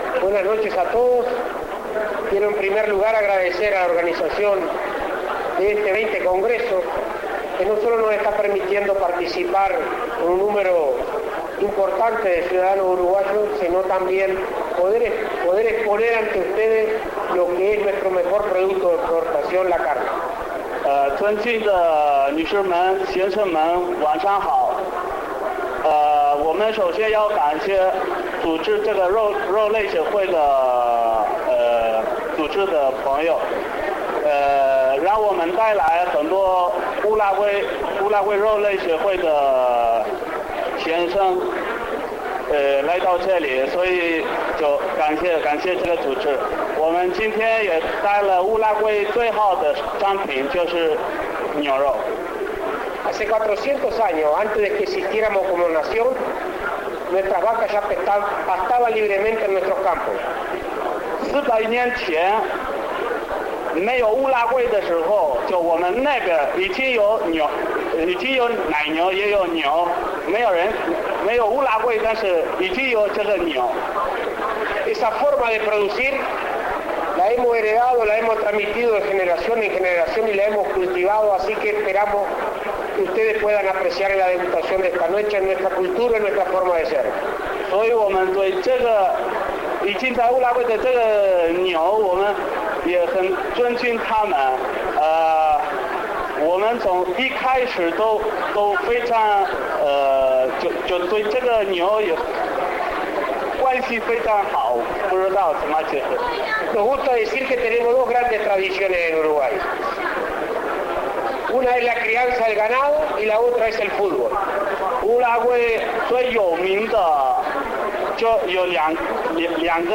AUDIO Presidente de INAC.
En el Beijing Continental Grand Hotel, a pocos metros del Centro de Convenciones donde sesiona el Congreso, se realizó la recepción inaugural donde la carne uruguaya fue protagonista.